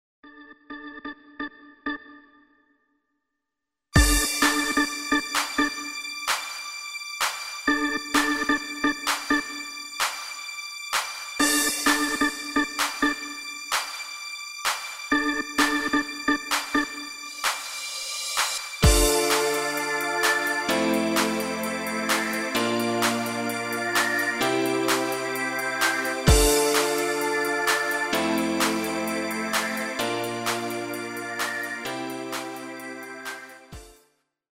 Demo/Koop midifile
Genre: Dance / Techno / HipHop / Jump
Demo's zijn eigen opnames van onze digitale arrangementen.